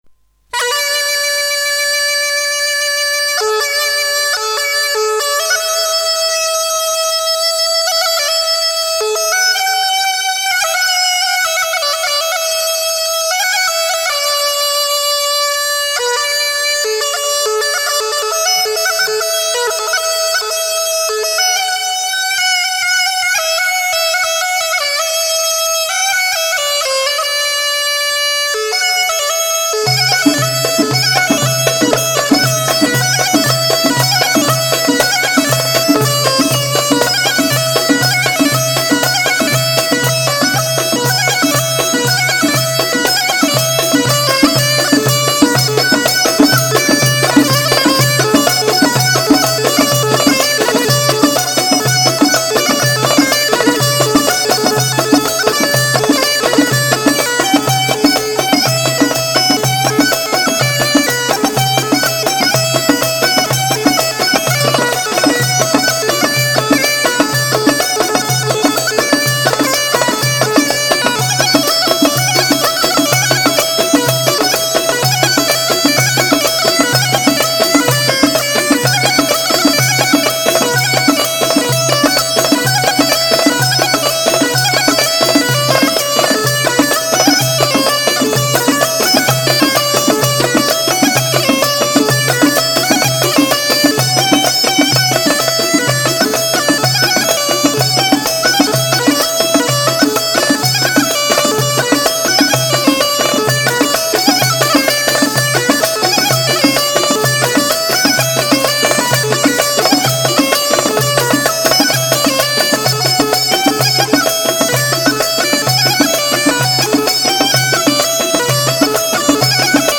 Bikalaam